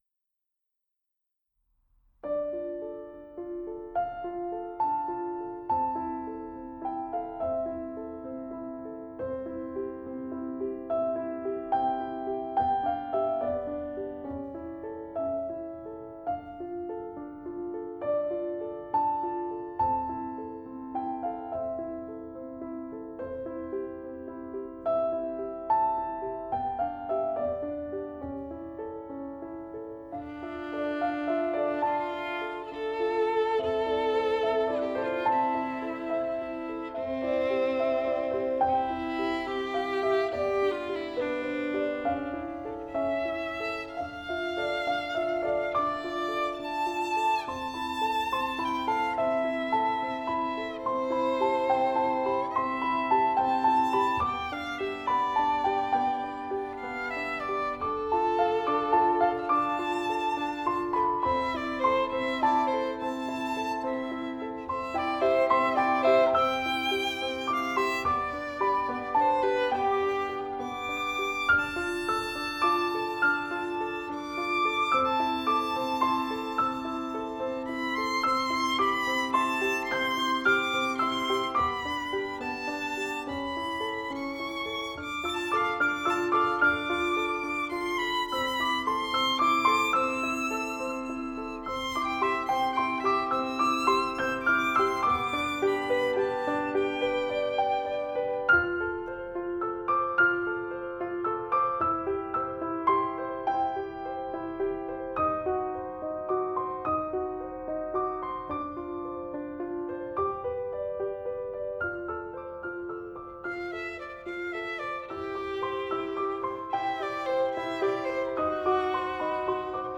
»Violine und Klavier (28)